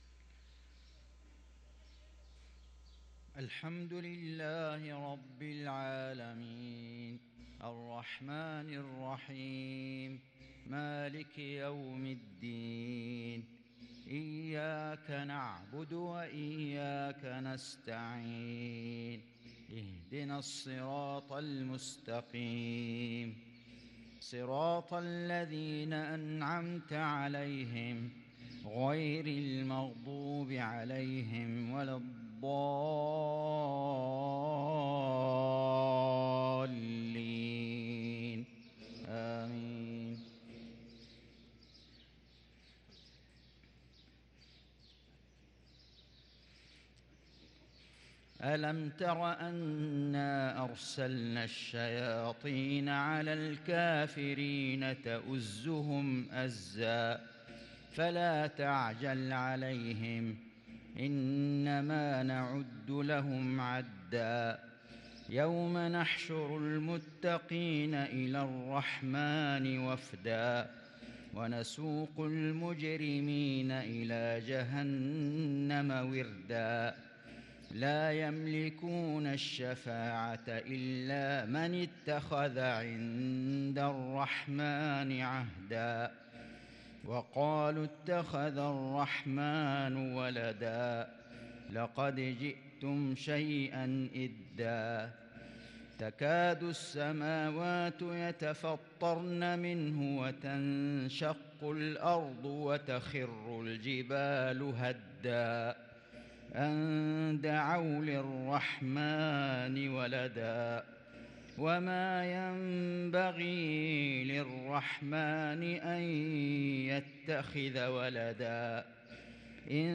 صلاة المغرب للقارئ فيصل غزاوي 6 جمادي الآخر 1444 هـ
تِلَاوَات الْحَرَمَيْن .